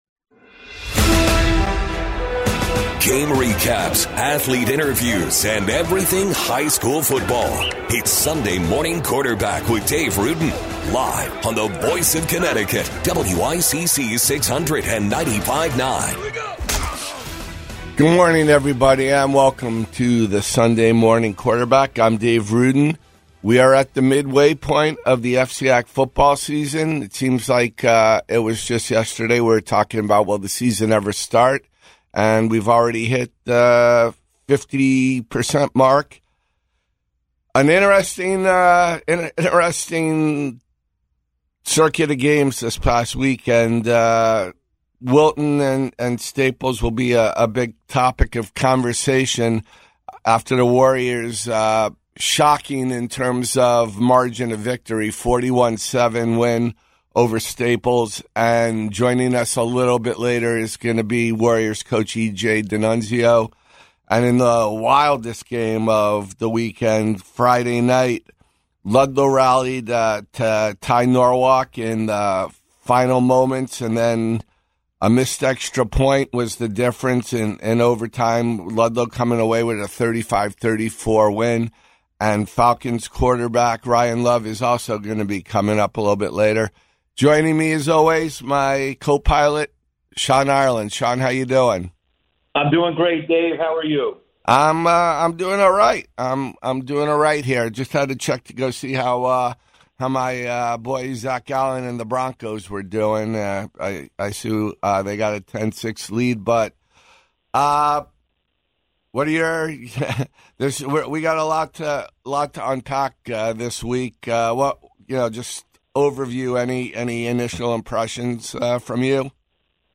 talks with coaches, teams, star athletes and more each week.